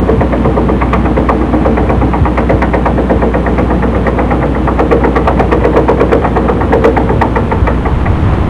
居室に響く、駐車機械稼動音
oto_tonton.wav